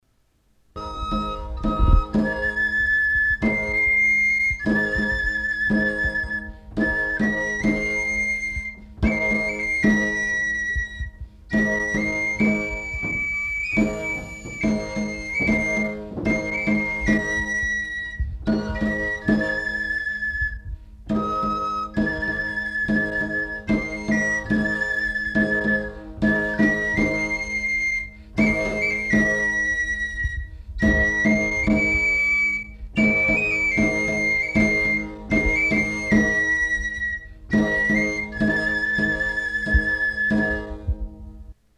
Aire culturelle : Béarn
Lieu : Castet
Genre : morceau instrumental
Instrument de musique : flûte à trois trous ; tambourin à cordes